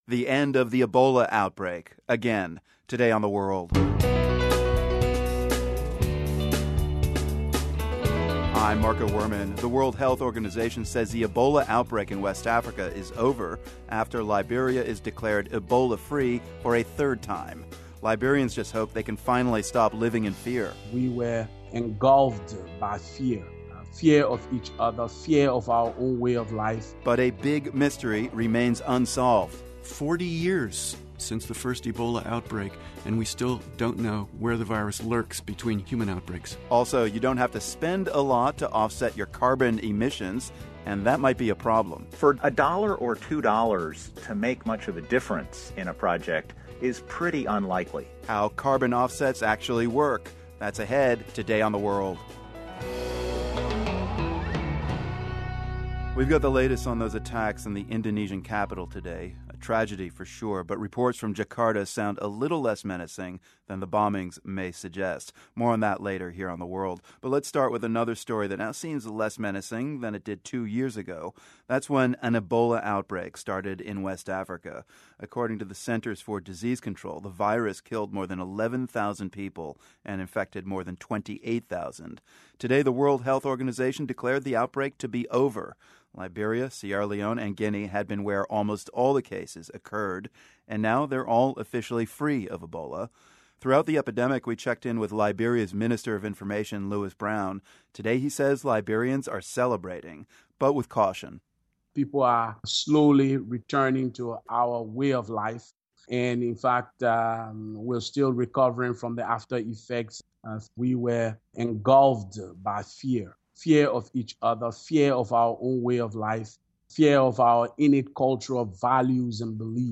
We hear from a Monrovia resident who says he's not sure how to react to the news anymore. Plus, we hear from a witness to the terrorist attacks in Jakarta today and find out more about the extremist groups that are active in Indonesia.